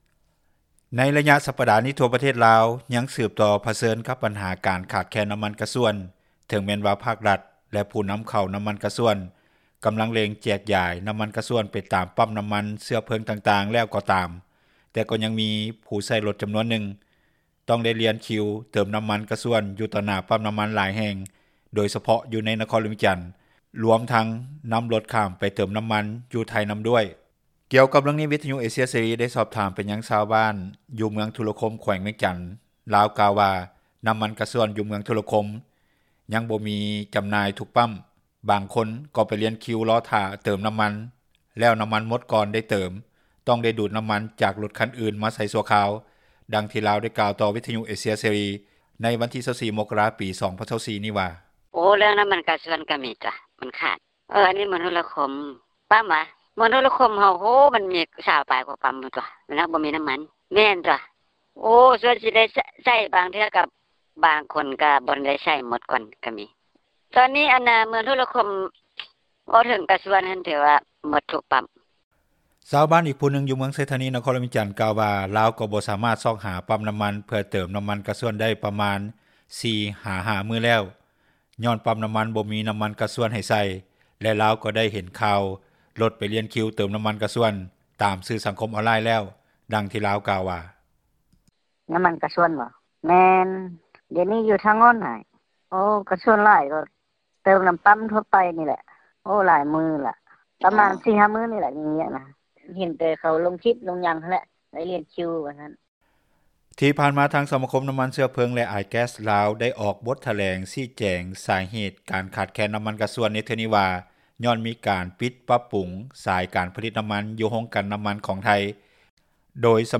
ກ່ຽວກັບເຣື່ອງນີ້, ວິທຍຸເອເຊັຽເສຣີ ໄດ້ສອບຖາມໄປຊາວບ້ານ ຢູ່ເມືອງທຸລະຄົມແຂວງວຽງຈັນ. ທ່ານ ກ່າວວ່າ ນ້ຳມັນກາຊວນຢູ່ເມືອງທຸລະຄົມ ຍັງບໍ່ມີຈຳໜ່າຍທຸກປ້ຳ. ບາງຄົນກໍໄປລຽນຄິວລໍຖ້າຕື່ມນ້ຳມັນແລ້ວນ້ຳມັນໝົດກ່ອນ ໄດ້ຕື່ມຕ້ອງໄດ້ດູດນ້ຳມັນຈາກຣົຖຄັນອື່ນມາໃສ່ຊົ່ວຄາວ.
ຊາວລາວຈໍານວນຫຼາຍ ທີ່ສາມາດຂັບຣົຖຂ້າມໄປປະເທດໄທຍ ໄປຕື່ມນ້ຳມັນກາຊວນ ຢູ່ໄທຍນຳດ້ວຍ ດັ່ງທີ່ພະນັກງານປ້ຳນ້ຳມັນ ນາງນຶ່ງ ຢູ່ແຂວງໜອງຄາຍ ປະເທດໄທຍ ກ່າວວ່າ ມີຣົຖກະບະ ແລະຣົຖຈິບລາວ ເຂົ້າມາຕື່ມນ້ຳມັນ ຢູ່ໃນປ້ຳຂອງນາງ ເປັນຈຳນວນຫຼາຍ ໃນໄລຍະນີ້.